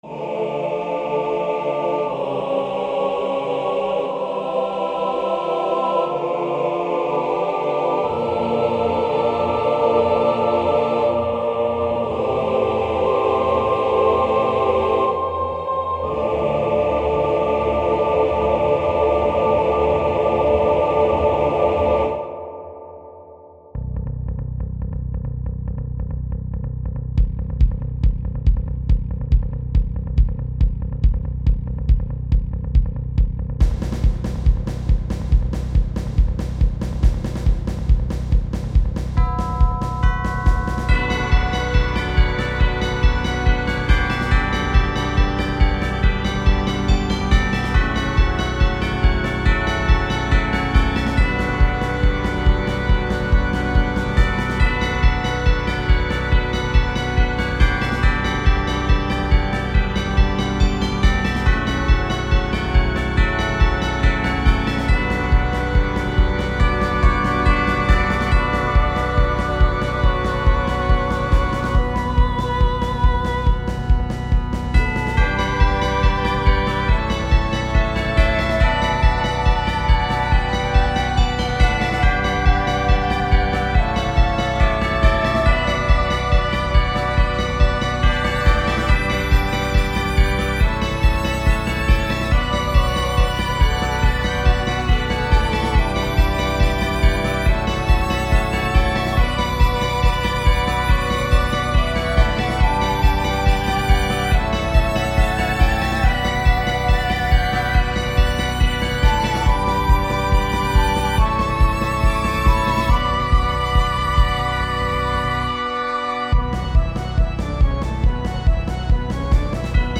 I had been playing a lot of RPGs lately, and I was inspired to make a big battle theme of my own.
orchestral music
instrumental organ bells
I was really envisioning being a battle in a church in a jrpg game on the GBA.
Fells like a game from the 90s!